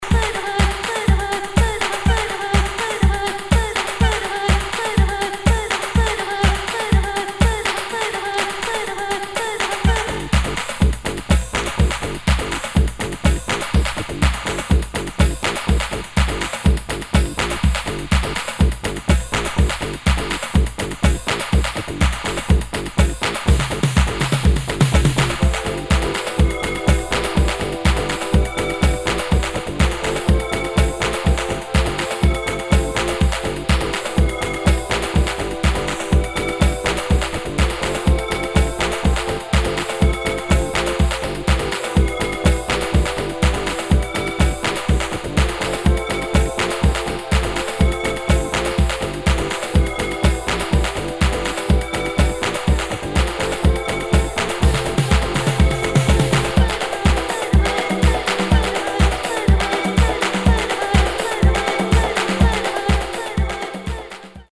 [ HOUSE | TECHNO ]